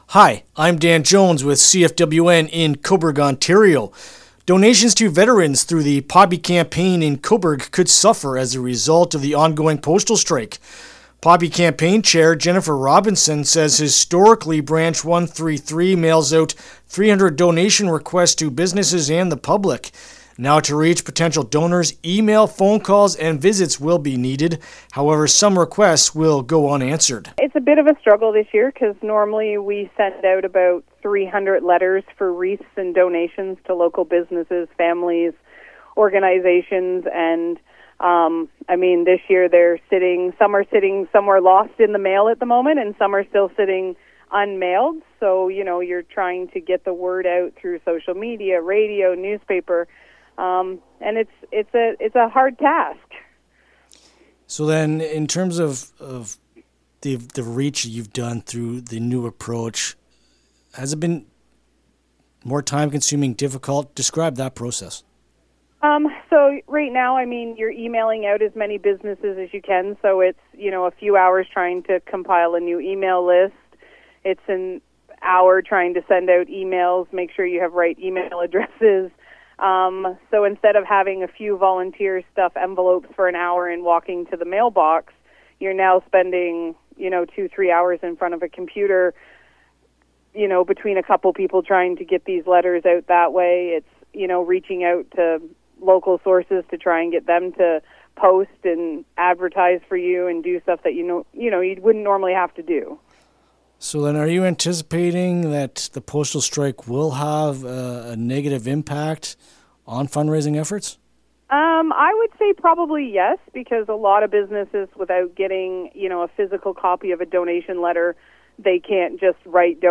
Cobourg-Legion-Poppy-Campaign-Interview-LJI.mp3